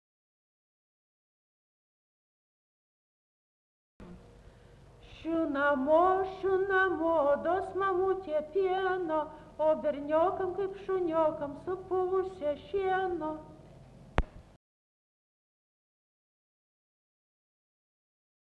Erdvinė aprėptis Kaunas
Atlikimo pubūdis vokalinis